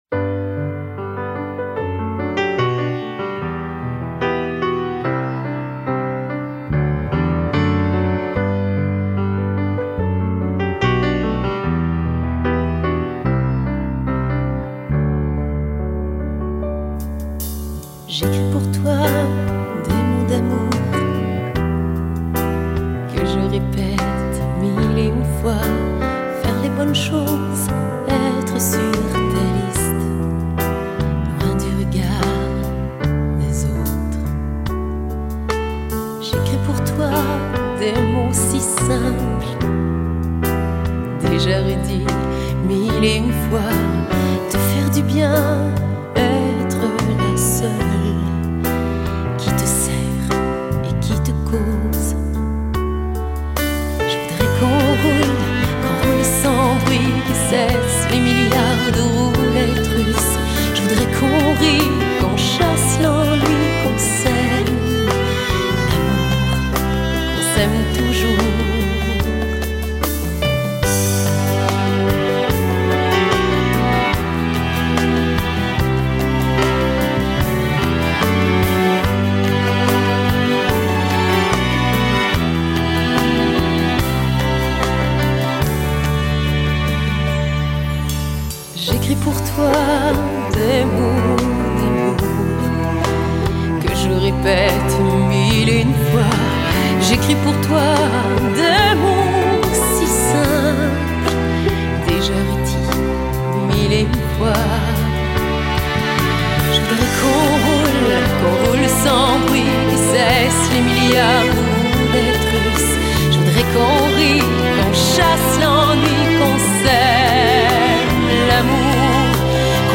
橫跨流行古典、加拿大英法語雙聲跨界音樂新天后
上帝賜予水晶般清澄透澈嗓音獨樹一格
● 這張結合聲樂美學，回歸新世紀空靈，更勝流行音樂質感的誠意專輯，是眾多商業包裝中難得一見清新佳作。
声音很甜~~
这种女声最喜欢啦